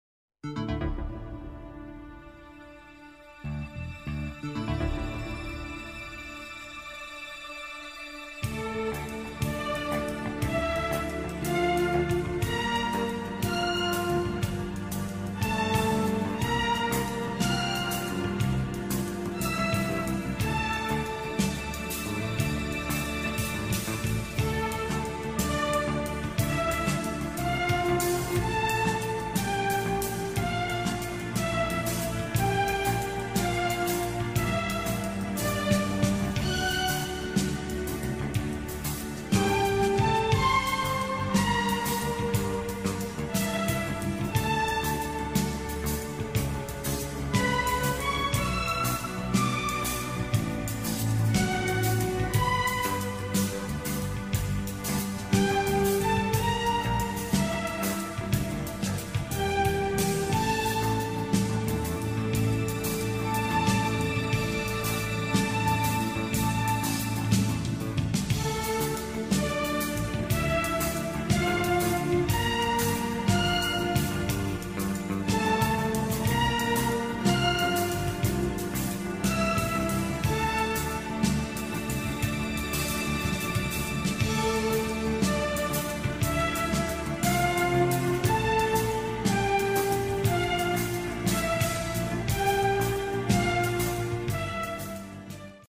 The Fish Eagle 🐟 sound effects free download
The Osprey On The Beach ⛱ Any Day In MiamI You Get To Watch Them Hunt 🦅